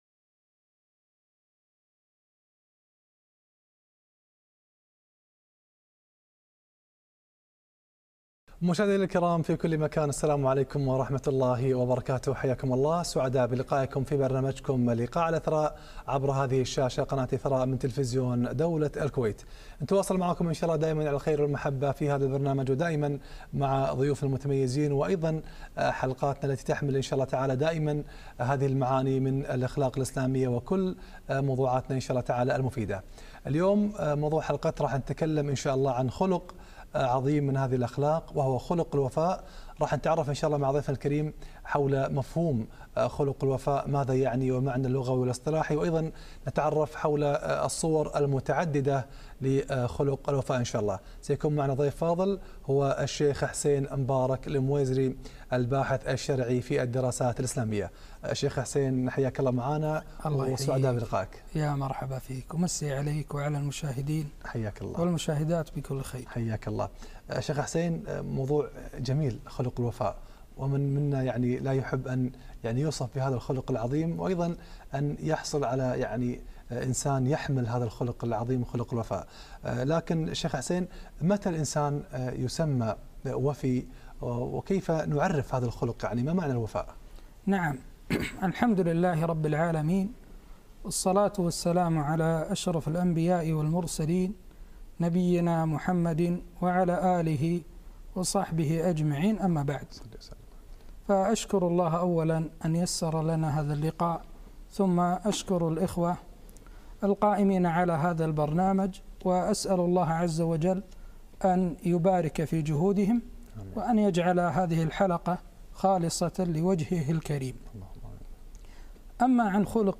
خلق الوفاء - لقاء إذاعي